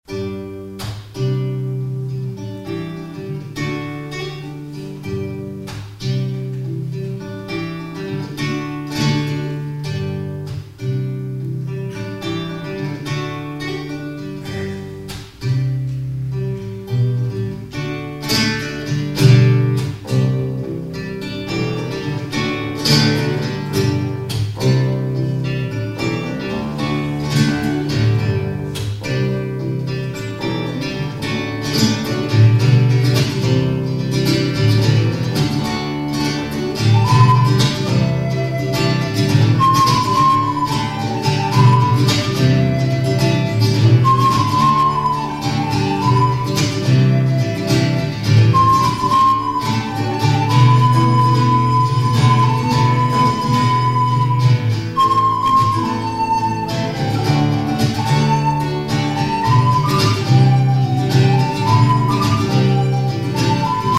mit Gitarre, Bass und Flöte